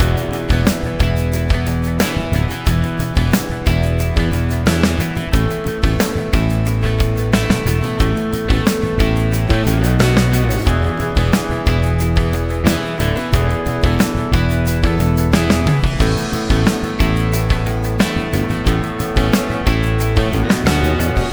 Habe es gerade mal auf einen Rough Mix draufgelegt. Eindruck: deutlich mehr Transienten, mehr Höhen, Mid/Side Verhältnis ändert sich, manche Instrumente kommen deutlich nach vorne, Tiefmitten werden etwas ausgedünnt. Leider macht Intensity immer eine leichte Pegelanhebung - auch wenn LVL Comp aktiviert ist. Habe mal zwei Files (Vocals habe ich ausgelassen - sind noch nicht fertig) angehängt.